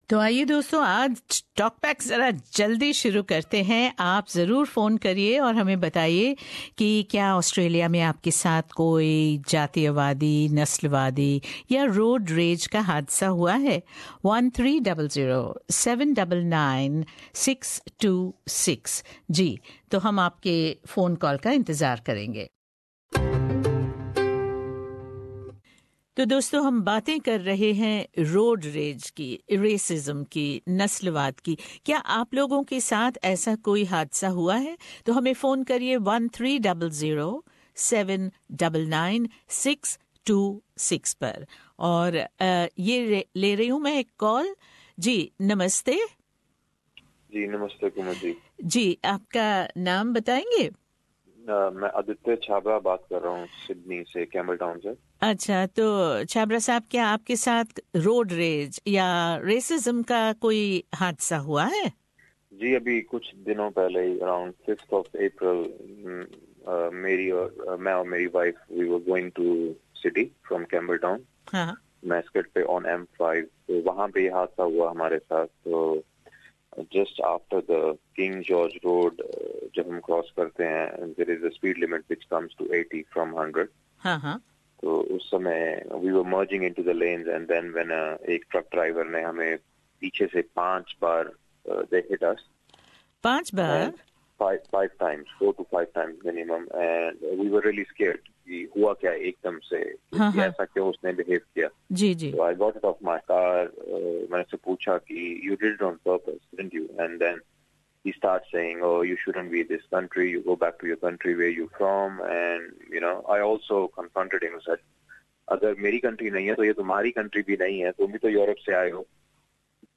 Monday Talkback on Hindi program discussed the thin line between road rage and racism in Australia
Several other members of the community shared their views and experiences on the talkback on Monday.